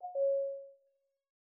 Knock Notification 13.wav